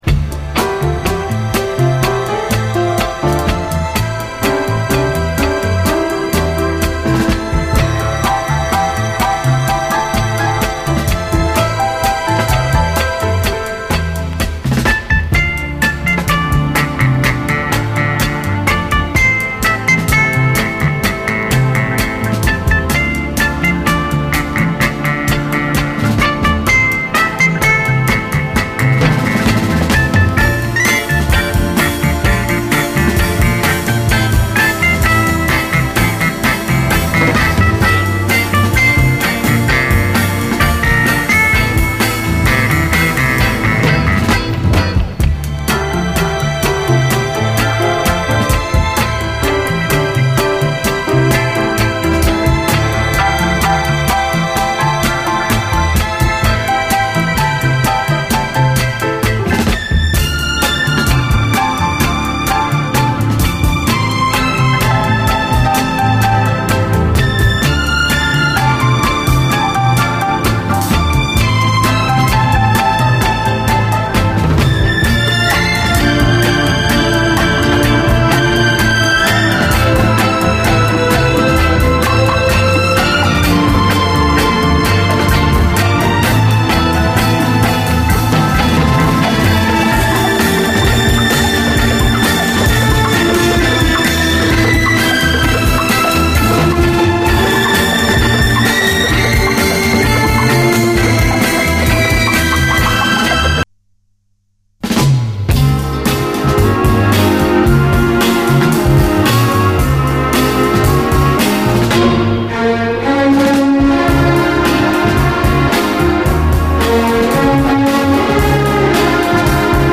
JAZZ FUNK / SOUL JAZZ, JAZZ
内容最高のエレガント・ソウル・ジャズ！